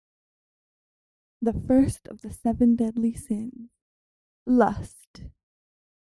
描述：女性，口语，七宗罪
Tag: 7 致命的 口语